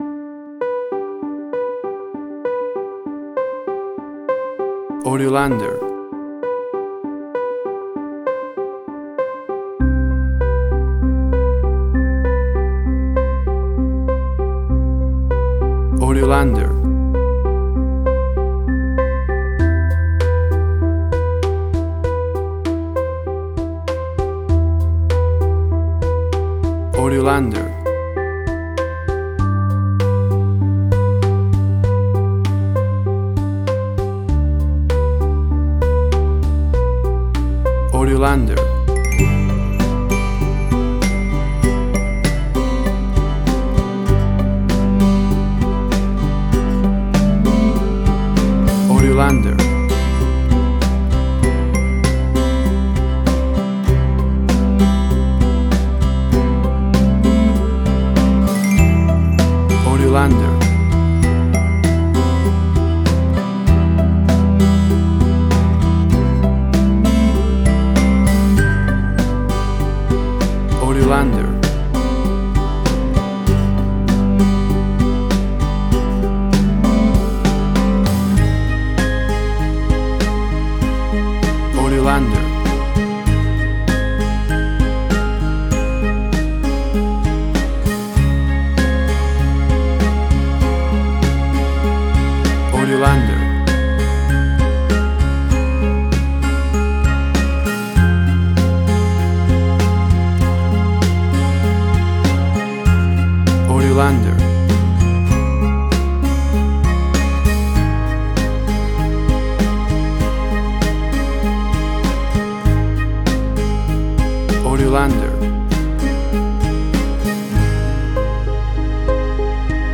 Suspense, Drama, Quirky, Emotional.
Tempo (BPM): 98